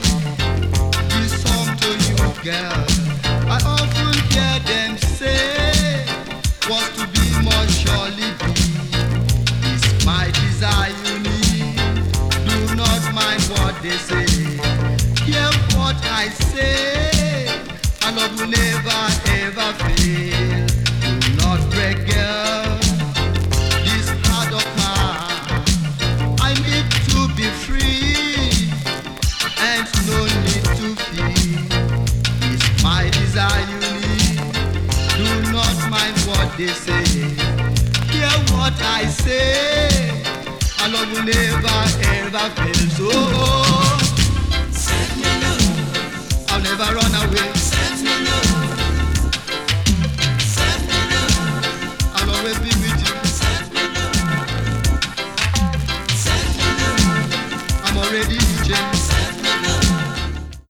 打ち込みのドラムや随所で見られるシンセ・ワークなど
80s AFRO 詳細を表示する